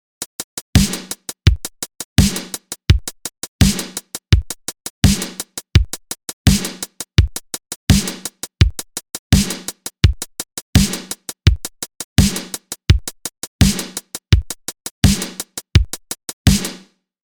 "Civilization of the Mind" - synthwave/electronic
Hey guys - looking for impressions on this new synth track for an electronic project I'm doing.